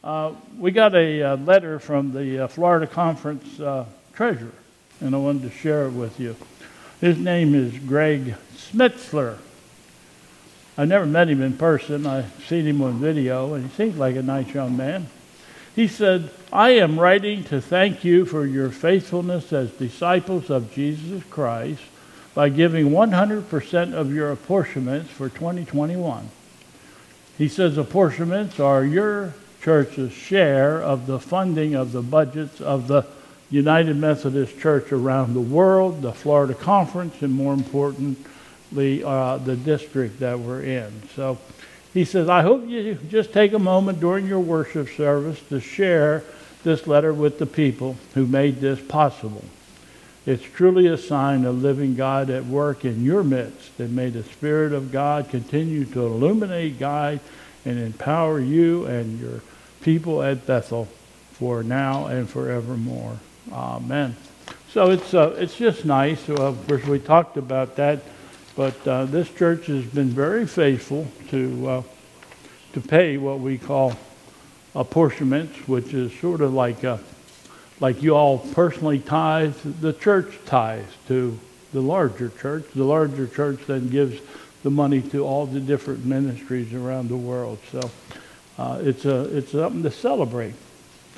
2022 Bethel Covid Time Service
For Pentecost, a Hymn and Litany celebrating the Birthday of the Church;